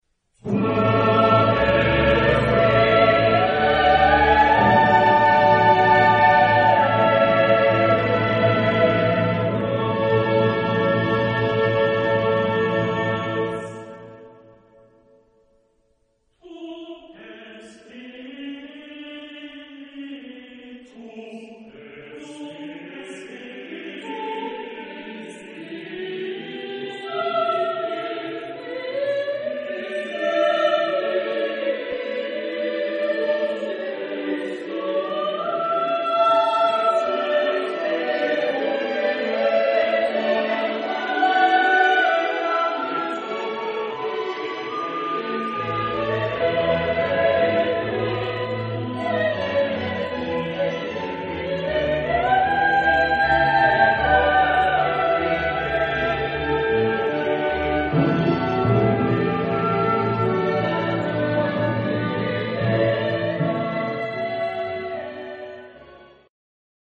Genre-Stil-Form: geistlich ; romantisch ; Motette